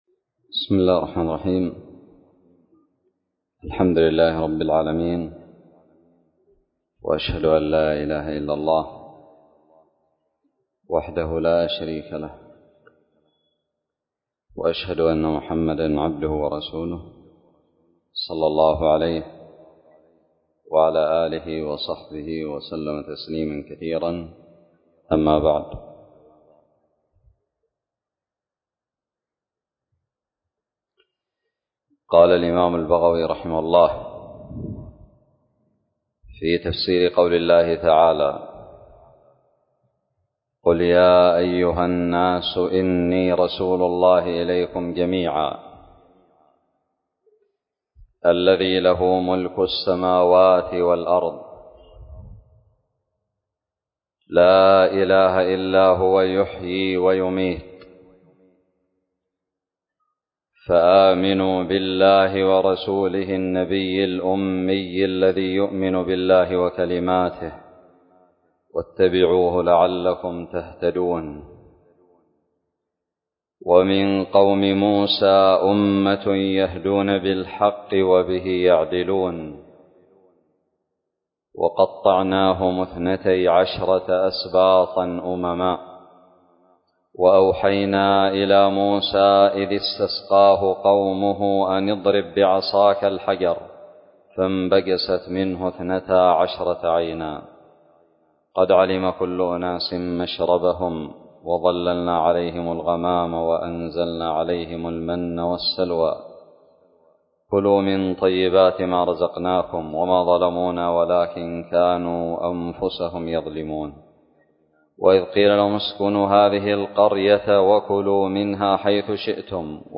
الدرس السادس والثلاثون من تفسير سورة الأعراف من تفسير البغوي
ألقيت بدار الحديث السلفية للعلوم الشرعية بالضالع